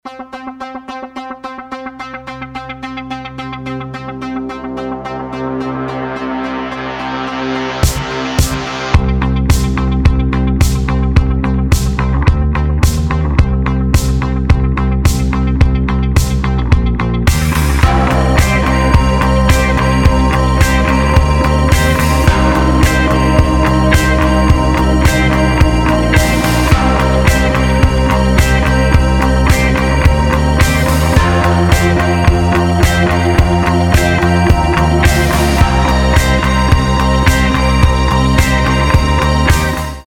• Качество: 320, Stereo
атмосферные
Electronic
без слов
alternative
Electronic Rock
Стиль: coldwave, post-punk